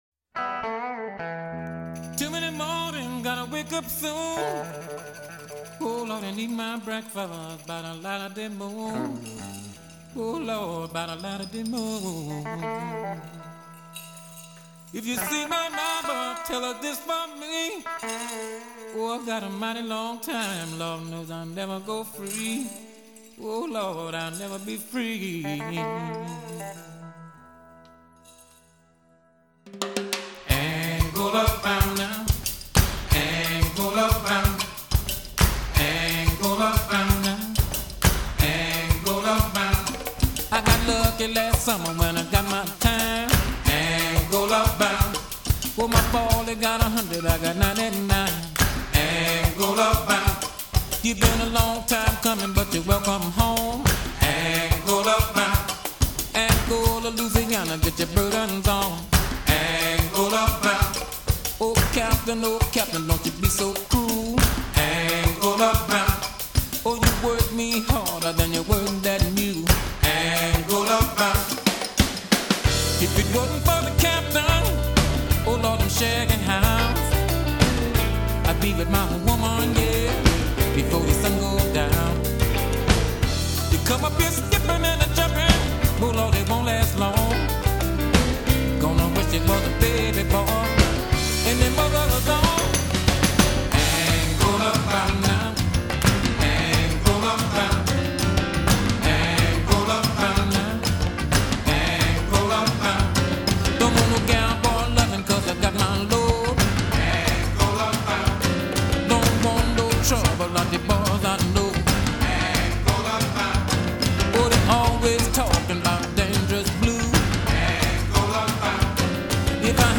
类型：R&B
此碟大部分乐曲皆有丰满低频衬底，有时敲击乐器更具震撼性。